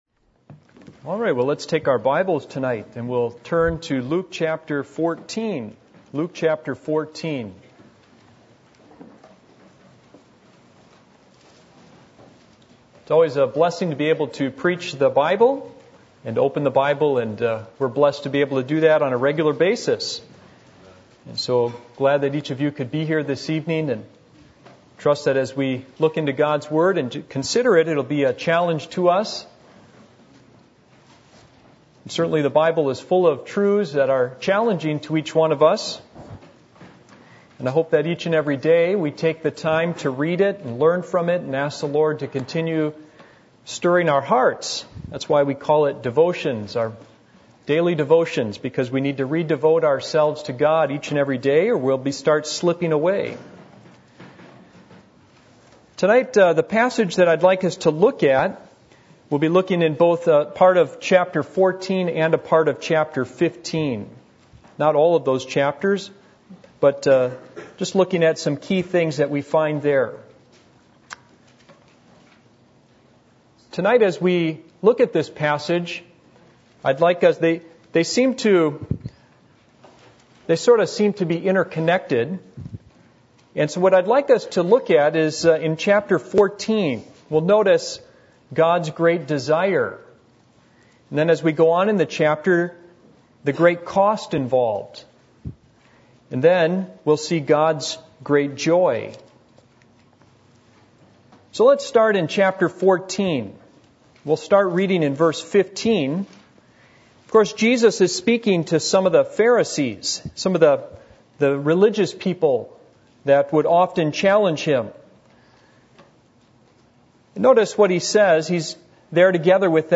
Passage: Luke 14:15-15:10 Service Type: Sunday Evening %todo_render% « Remember The Blood Are You Going Forward Spiritually